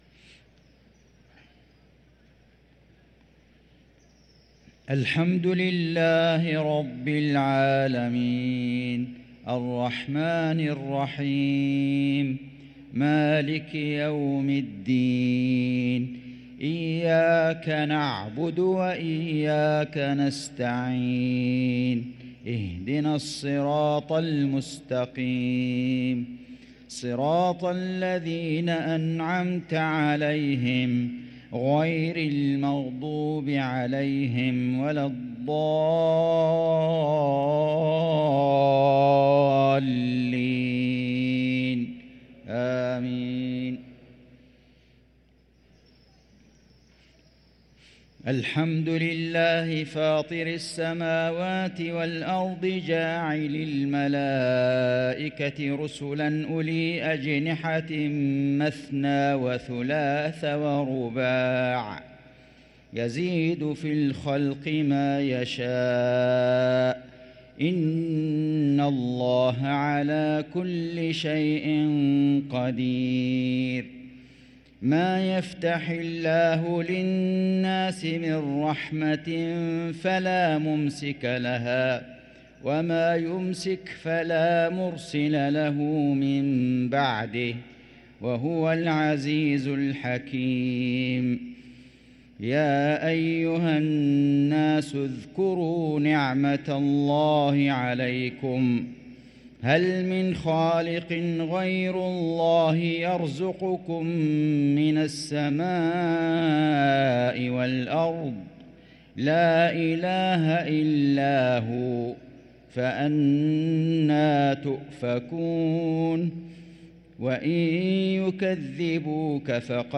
صلاة المغرب للقارئ فيصل غزاوي 4 رمضان 1444 هـ
تِلَاوَات الْحَرَمَيْن .